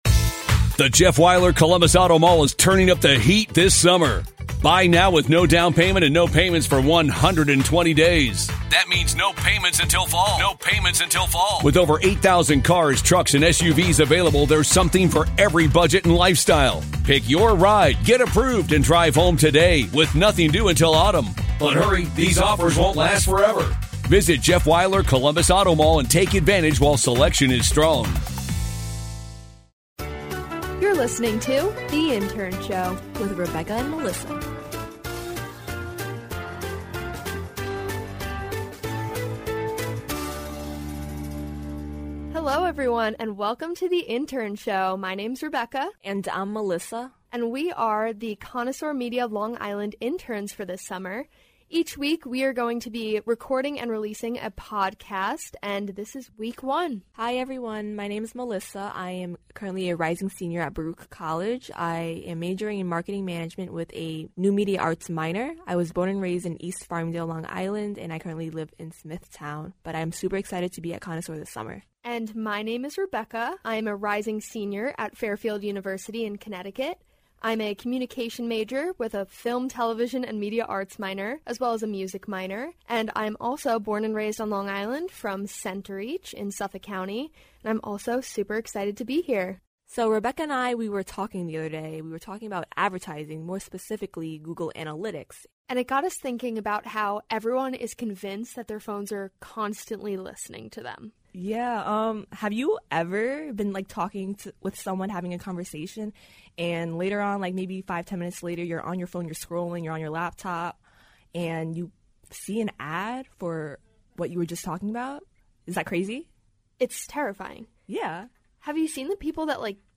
take over a studio to discuss, well, pretty much anything this episode.